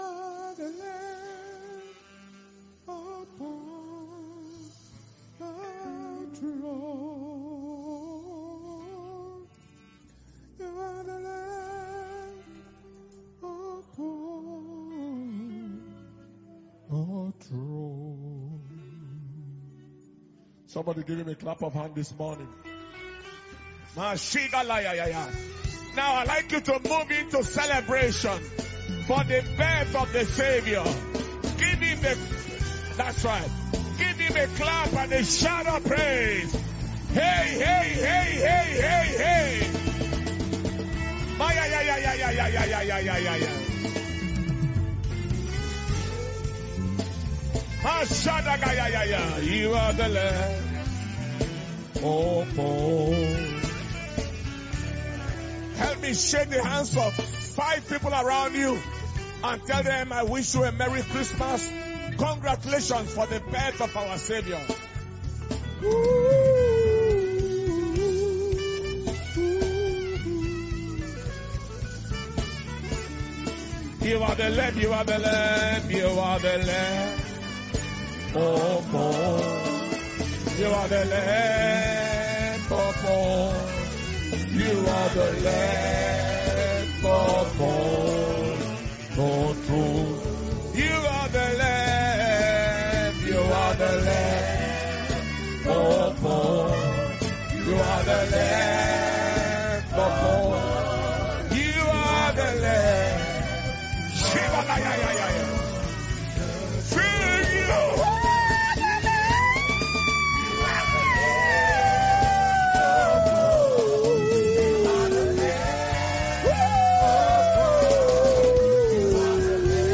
Christmas Celebration Service – Saturday, 25th December 2021